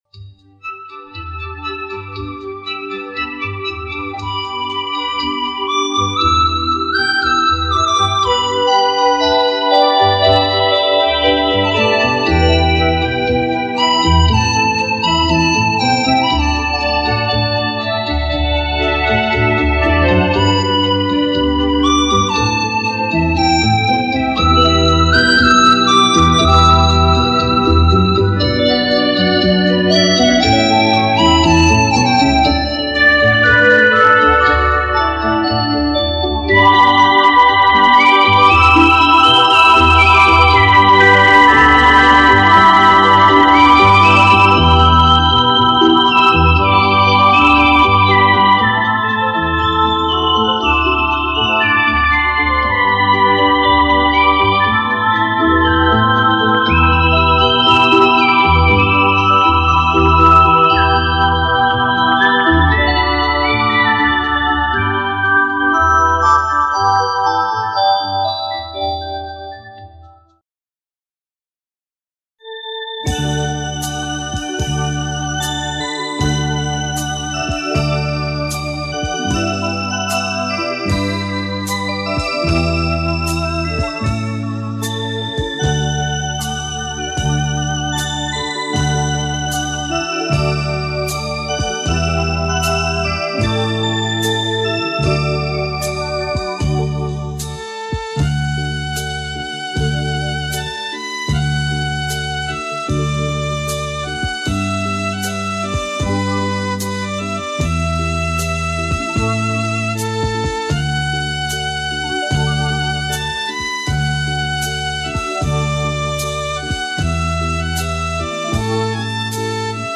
以其欢快动听的旋律